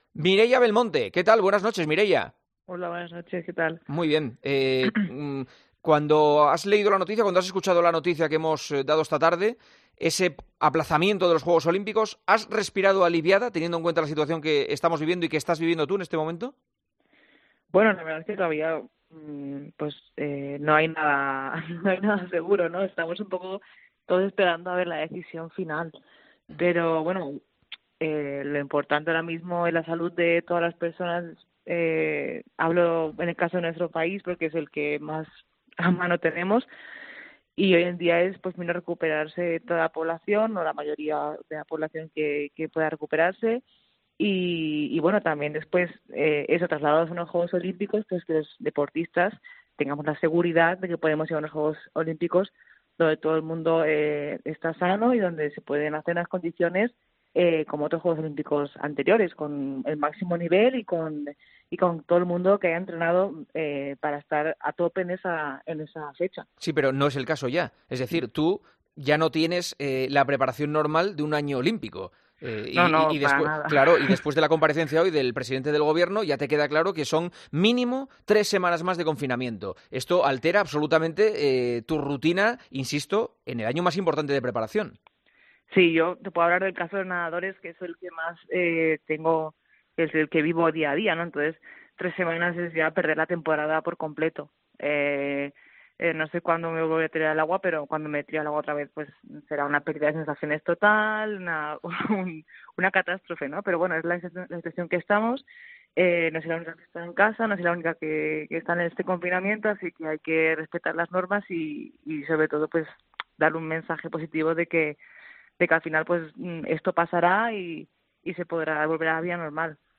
La nadadora y el palista hablaron en Tiempo de Juego, tras saber que el COI aplaza la cita de Tokio: "Queremos que todo el mundo llegue en condiciones".
Este domingo, Tiempo de Juego charló con dos de los iconos olímpicos que tiene el deporte español: la nadadora Mireia Belmonte y el palista Saúl Craviotto.